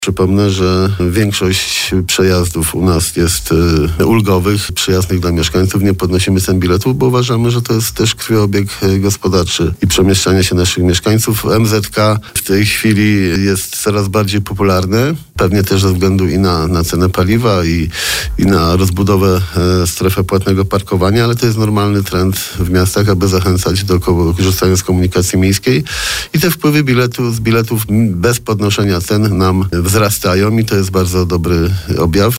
Na naszej antenie prezydent miasta mówił, że taka lokata w tym rankingu wynika z metodologii liczenia wskaźnika.